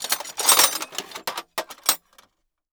TOOL_Toolbox_Handle_RR1_mono.wav